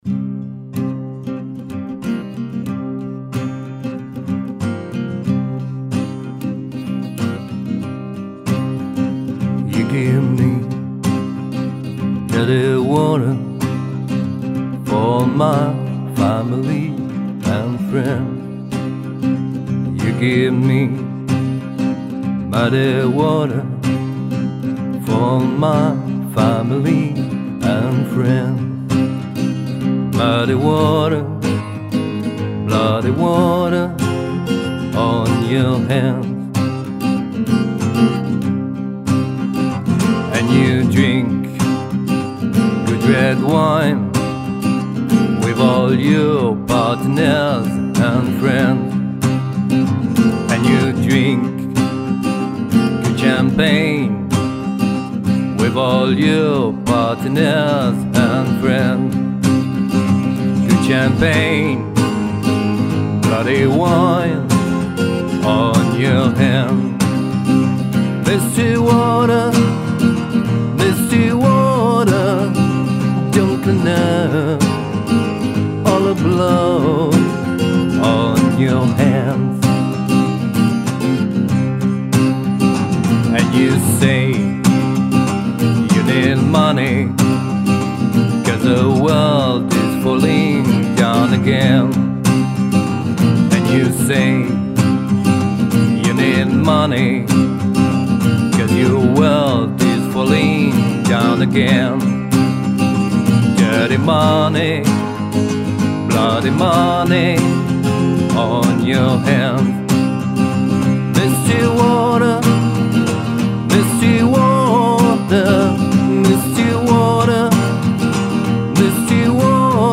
démos
guitare acoustique
harmonica